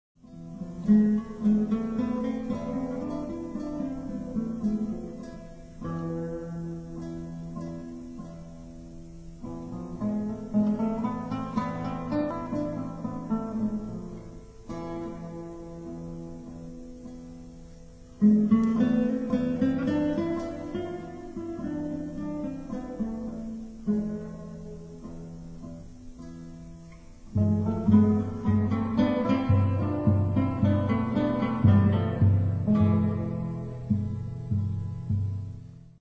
Gitarren solo